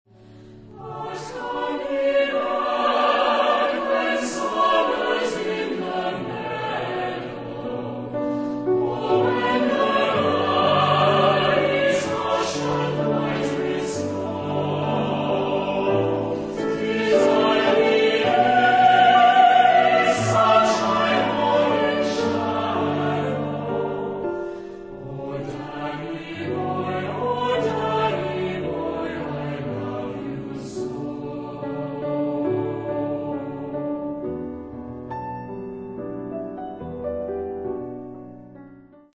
Genre-Style-Form: Romance ; Secular
Mood of the piece: supple
Type of Choir: SATB  (4 mixed voices )
Instrumentation: Piano  (1 instrumental part(s))
Tonality: D major